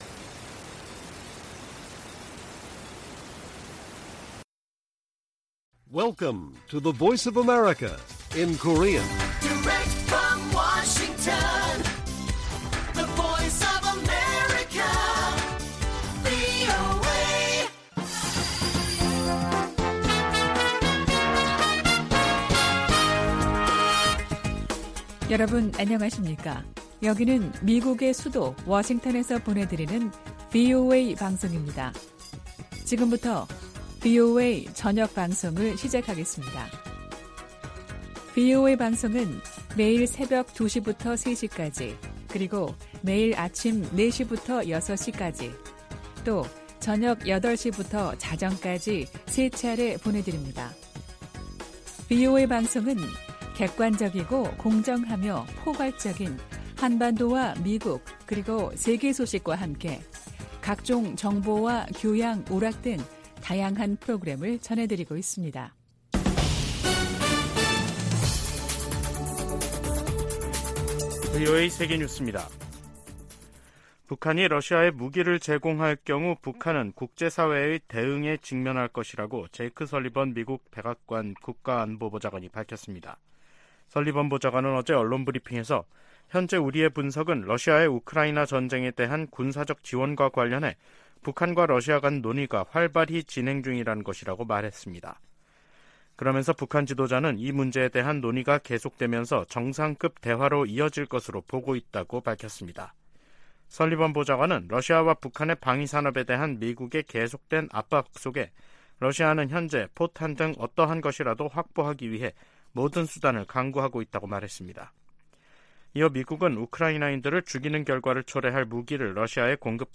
VOA 한국어 간판 뉴스 프로그램 '뉴스 투데이', 2023년 9월 6일 1부 방송입니다. 백악관은 북한과 러시아가 정상 회담을 추진하고 있다는 보도가 나온 가운데 양국 간 무기 협상을 중단하라고 촉구했습니다. 윤석열 한국 대통령도 북-러 군사협력을 시도하지 말라고 요구했습니다. 김정은 북한 국무위원장과 블라디미르 푸틴 러시아 대통령은 정상회담에서 양국 군사협력을 새로운 차원으로 진전시키는 중요한 합의를 발표할 것으로 미국 전문가들이 내다봤습니다.